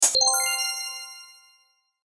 ting.ogg